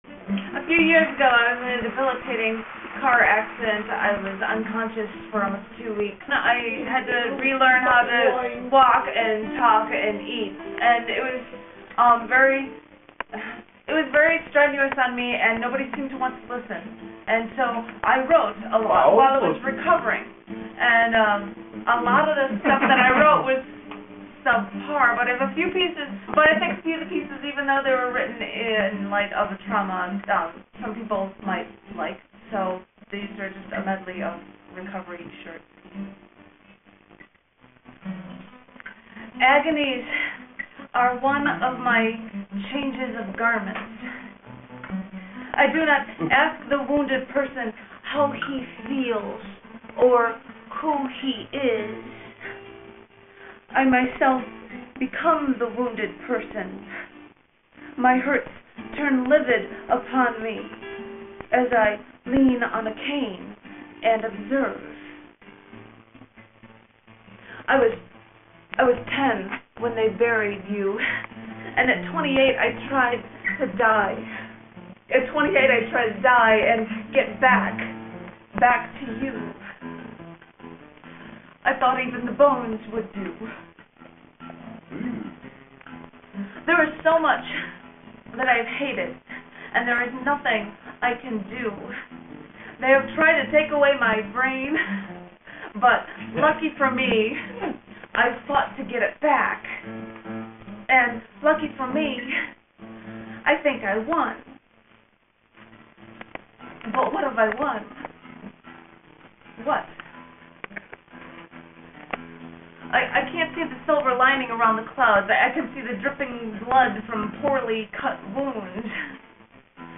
These live recording were made 3/13/02
in Hendersonville, outside of Nashville.